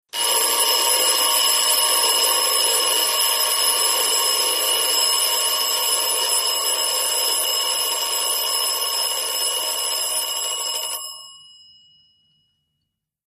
CLOCKS ALARM CLOCK: INT: Two bell alarm clock rings and runs out.